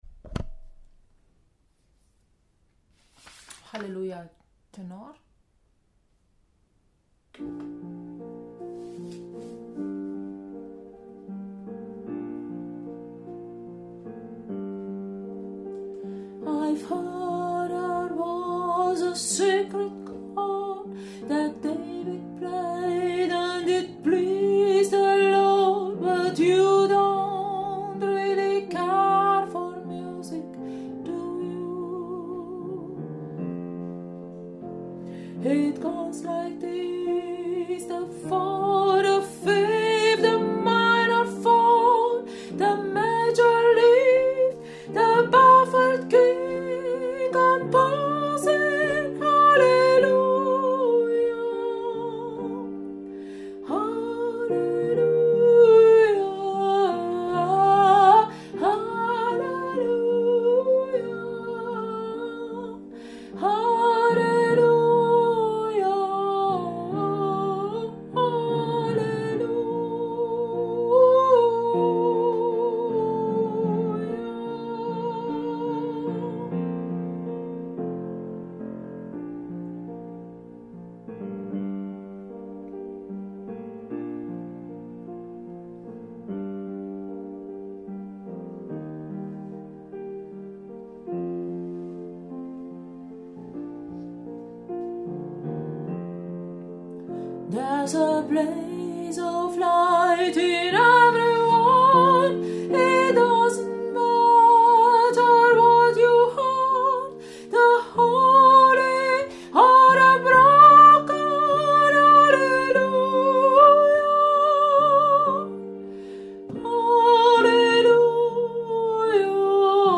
Hallelujah – Tenor